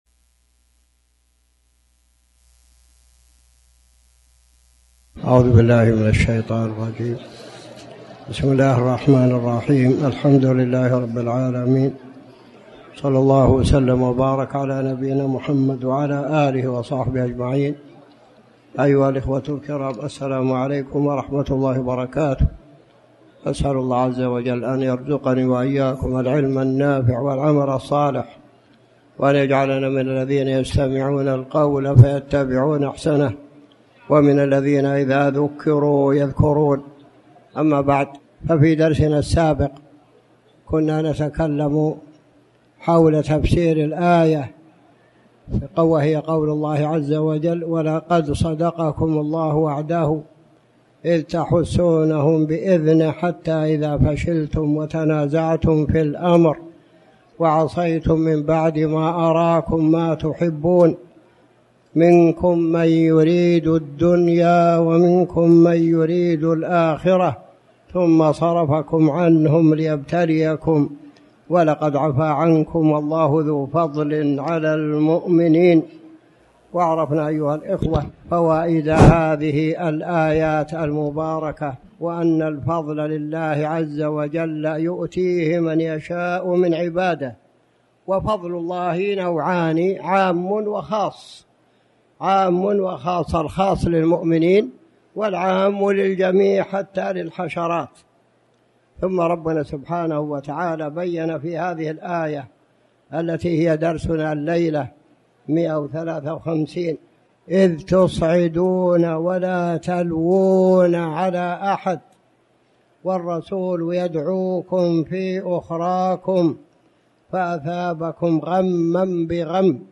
تاريخ النشر ١٧ رمضان ١٤٣٩ هـ المكان: المسجد الحرام الشيخ